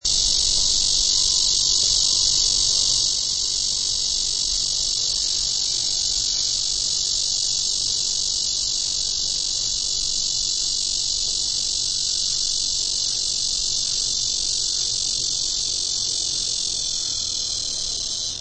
紅脈熊蟬 Cryptotympana atrata
南投縣 鹿谷鄉 溪頭
錄音環境 雜木林
雄紅脈熊蟬呼喚歌聲
Sennheiser 型號 ME 67